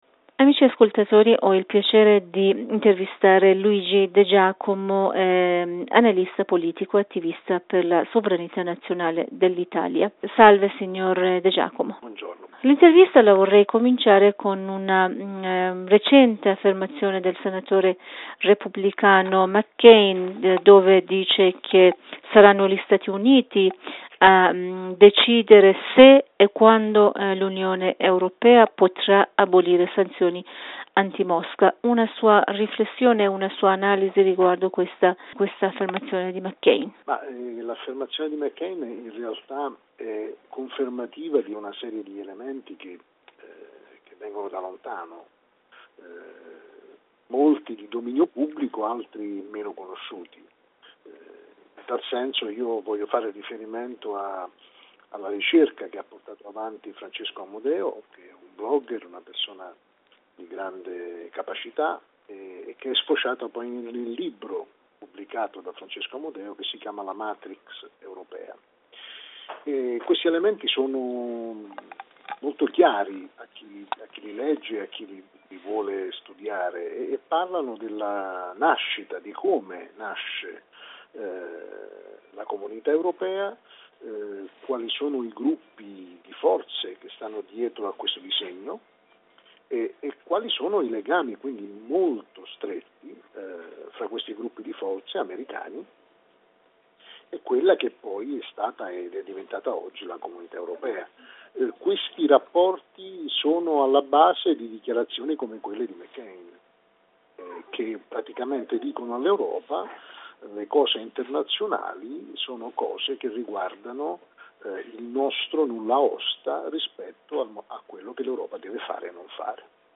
Potete ascoltare la versione integrale dell'intervista